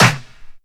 TM88 HardSnare.wav